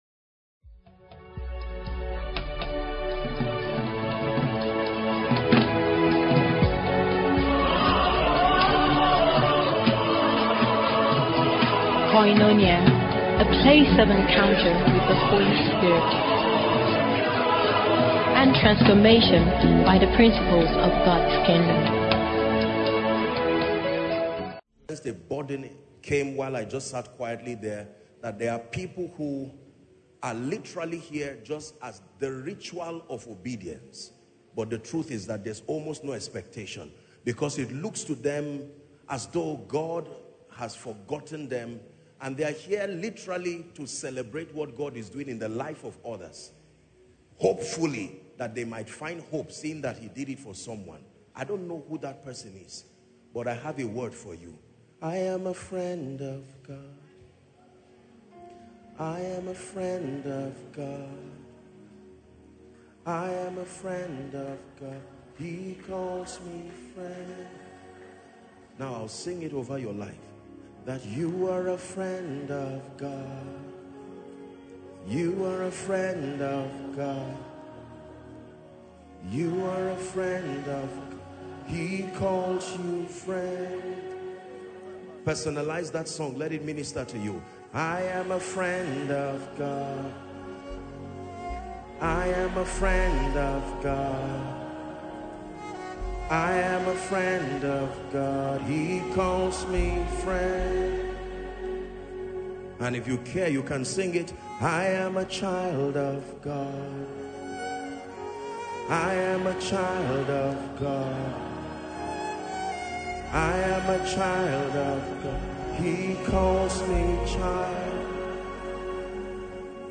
That was one of the strongest currents running through the March 2026 Miracle Service. A resounding truth emerged early and continued to echo throughout the gathering: the good fight of faith is necessary because realities that are finished in Christ do not automatically manifest in the life of the believer.